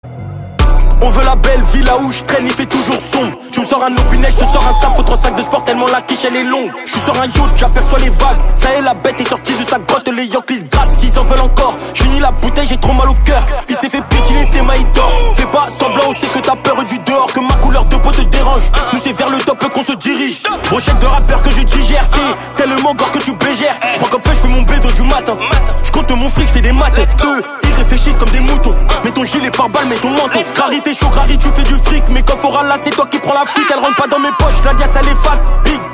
Rap / Hip Hop